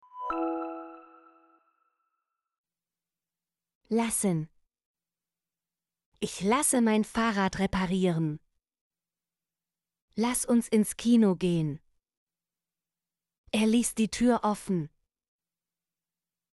lassen - Example Sentences & Pronunciation, German Frequency List